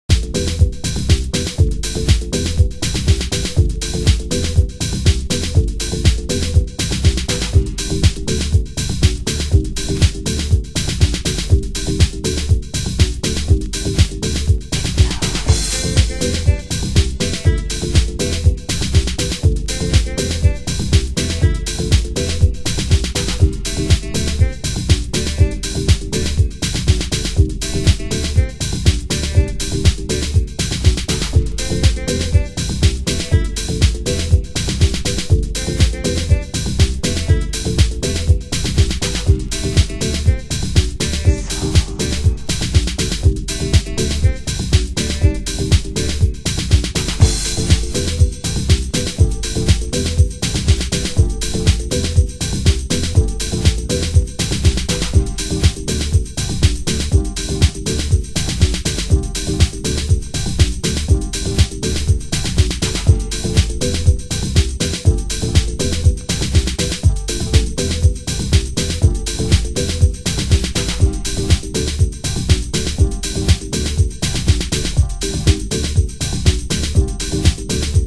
HOUSE MUSIC
盤質：A1にスレ傷 有/少しチリパチノイズ有